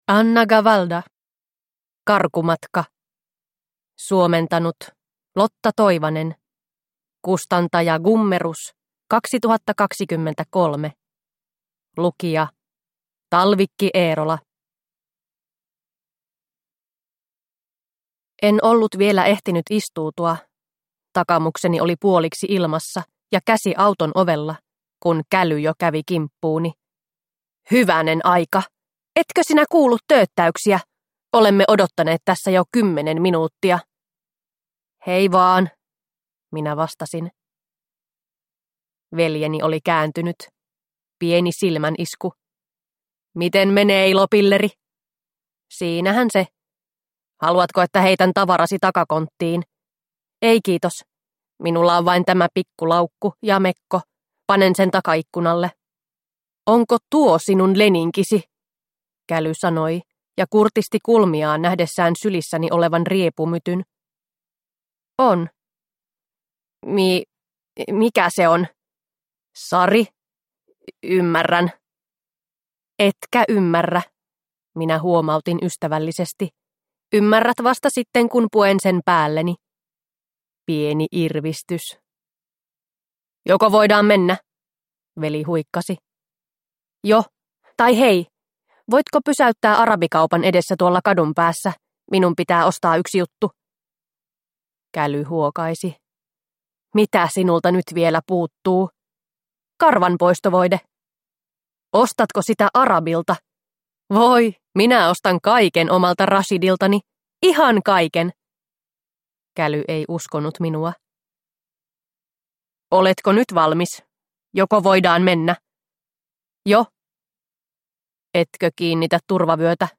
Karkumatka – Ljudbok – Laddas ner